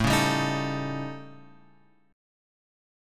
A 7th Flat 5th